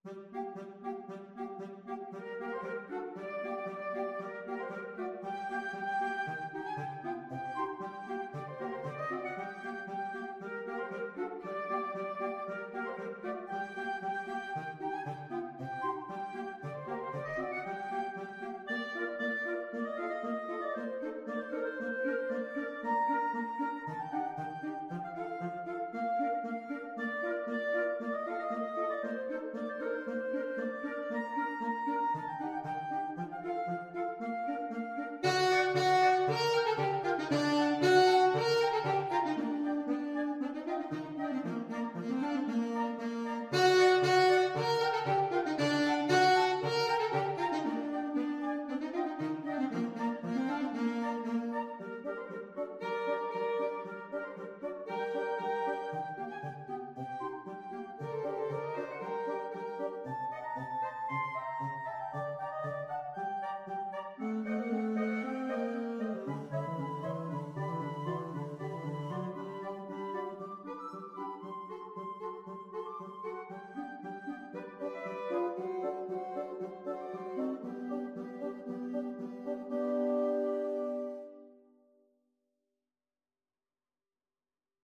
Flute
Alto Saxophone
Clarinet
Tenor Saxophone
Allegro moderato =116 (View more music marked Allegro)
2/4 (View more 2/4 Music)